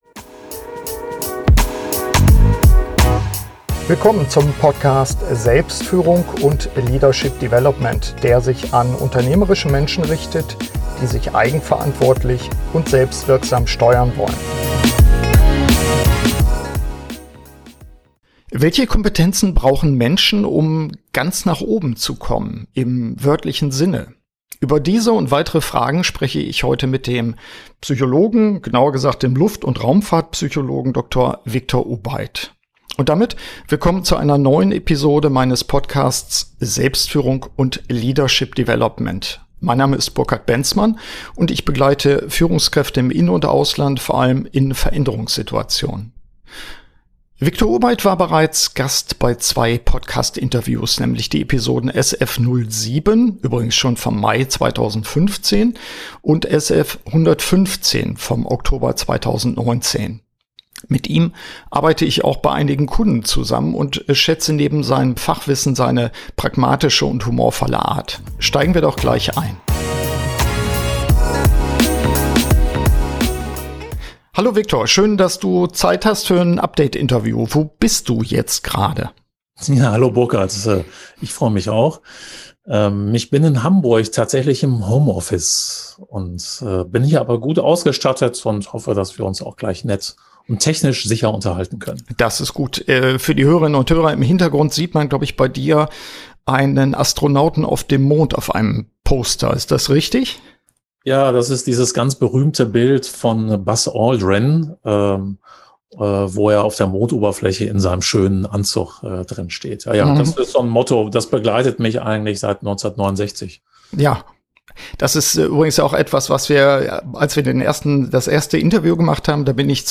Update-Interview